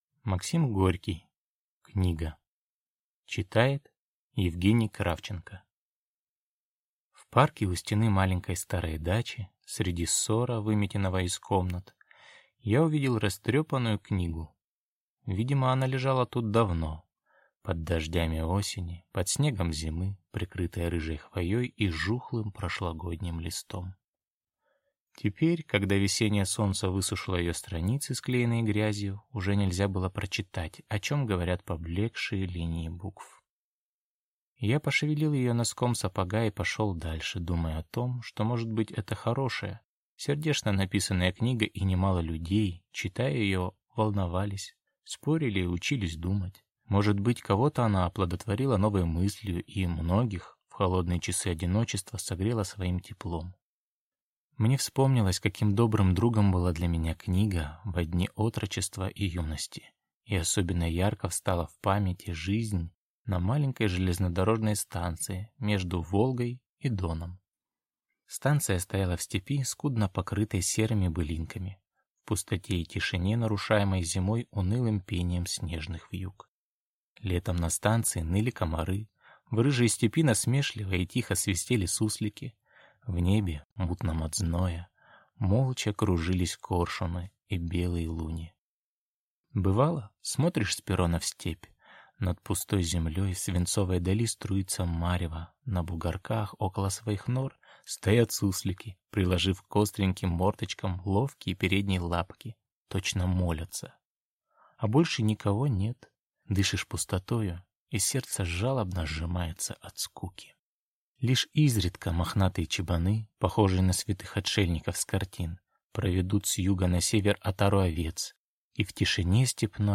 Аудиокнига Книга | Библиотека аудиокниг